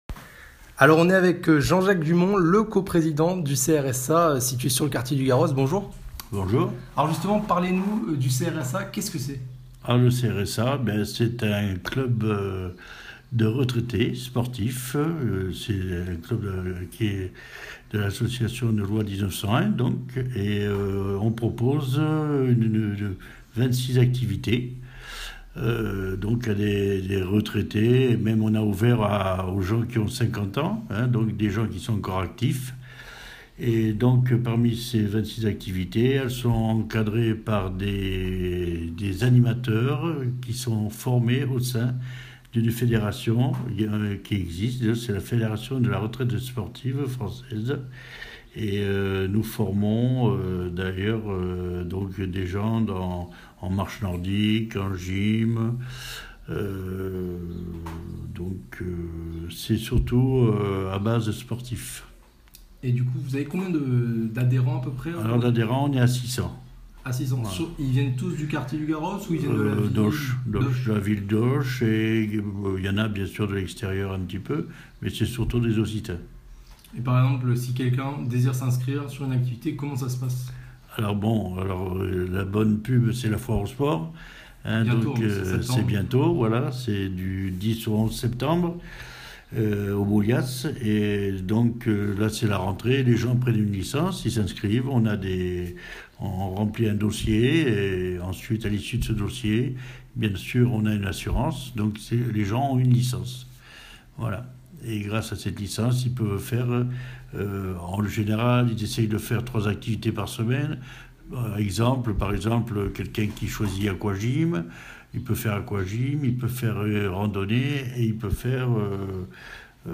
interview-4.mp3